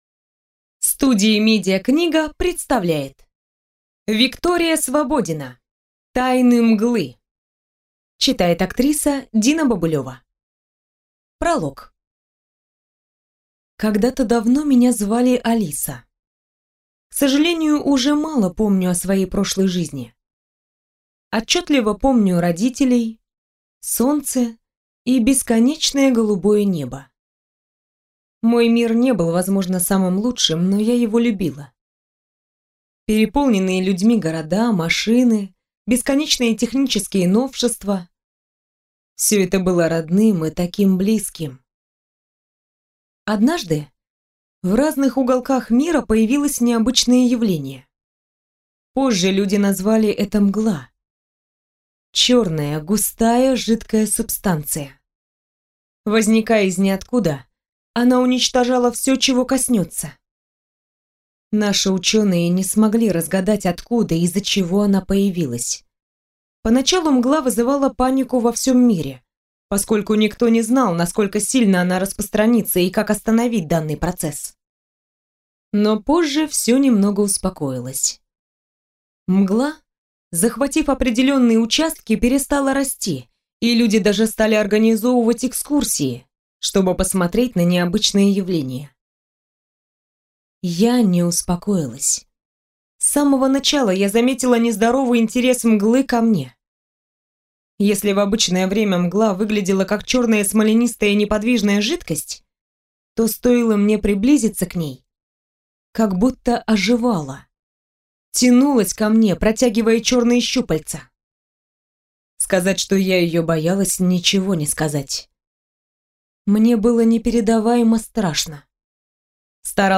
Аудиокнига Тайны Мглы | Библиотека аудиокниг
Прослушать и бесплатно скачать фрагмент аудиокниги